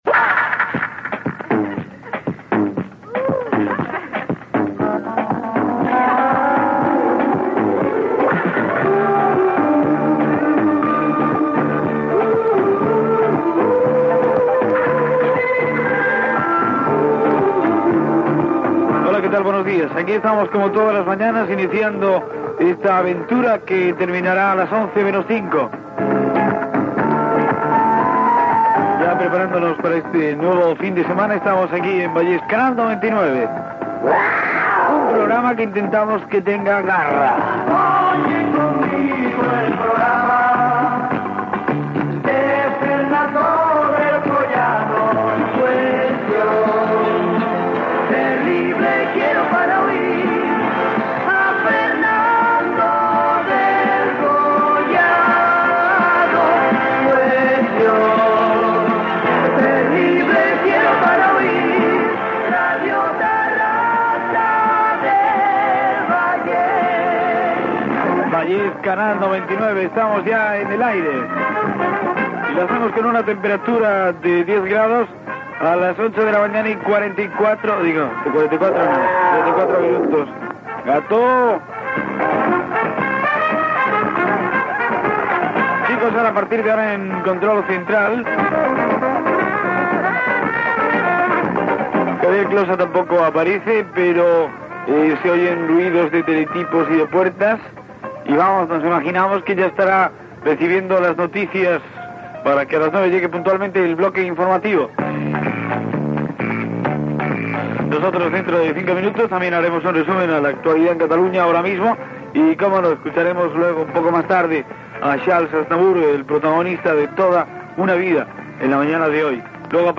Sintonia del programa amb la presentació dels continguts.
Entreteniment